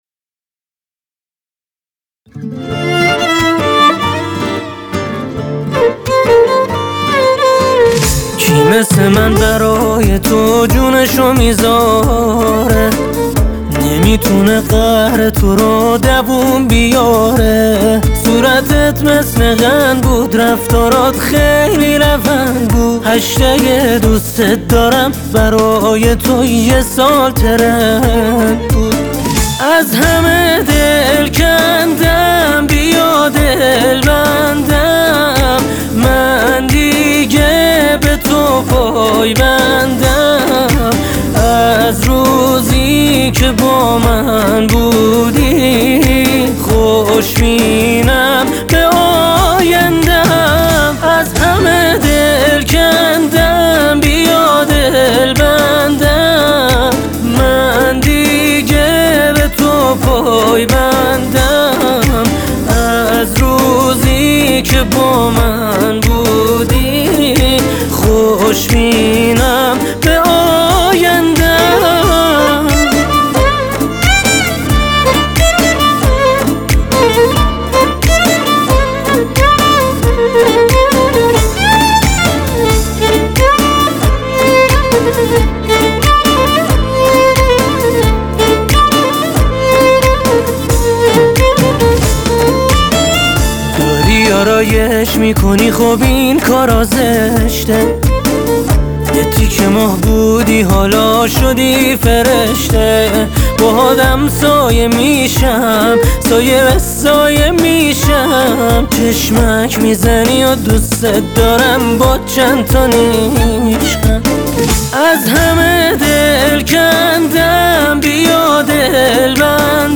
ویولن
آهنگ پاپ